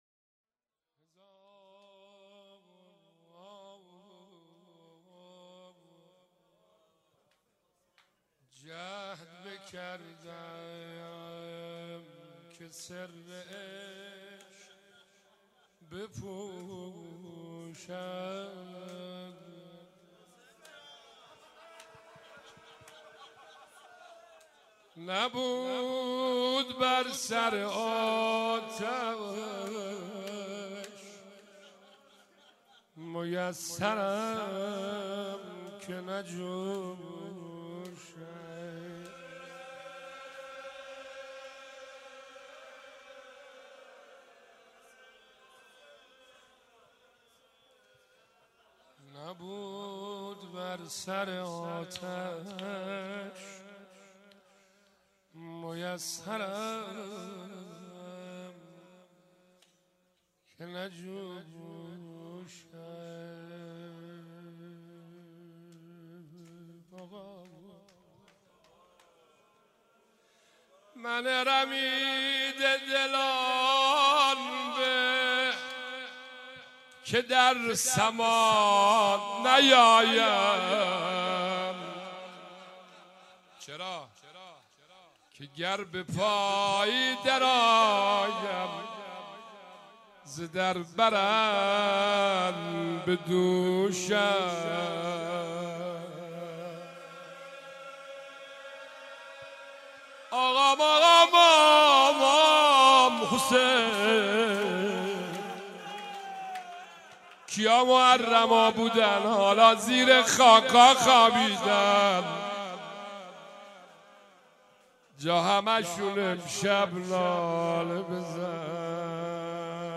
شب اول محرم97
روضه